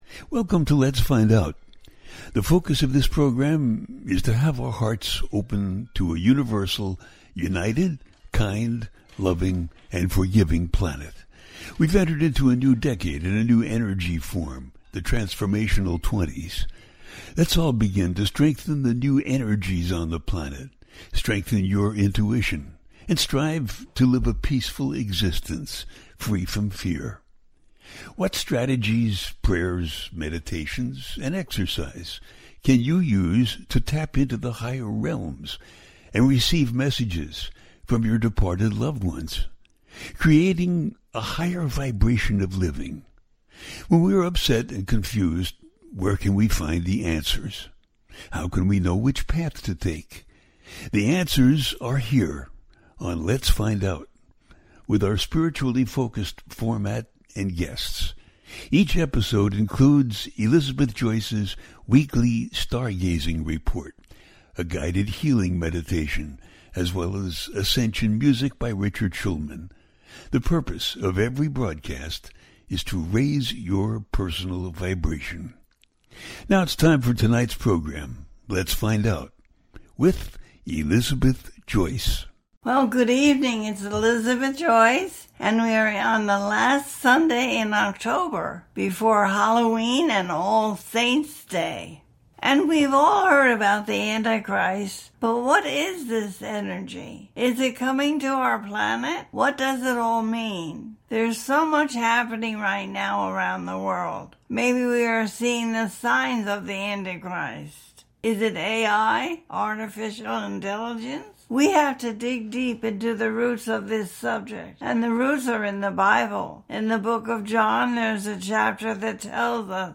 What is Anti-Christ - What is Autism - A teaching show
The listener can call in to ask a question on the air.
Each show ends with a guided meditation.